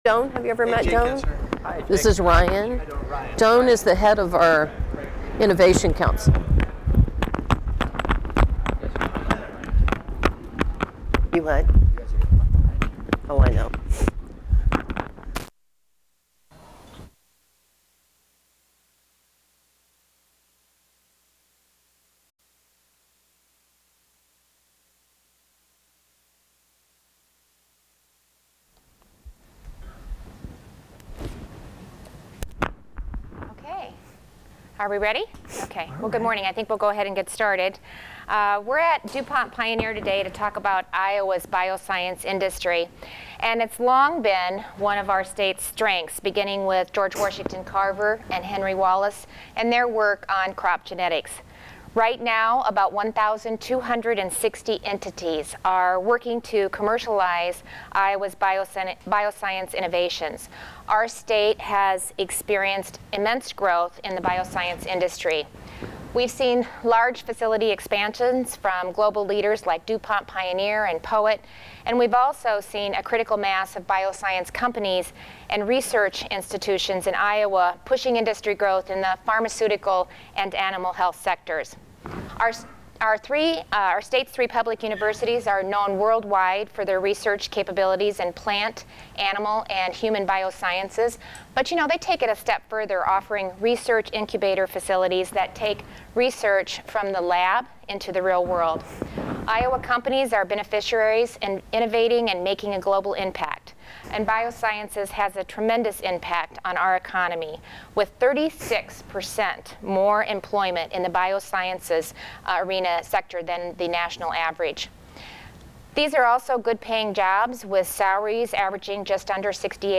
Reynolds made her comments during a news conference at DuPont Pioneer in Johnston. AUDIO of governor’s news conference Share this: Facebook Twitter LinkedIn WhatsApp Email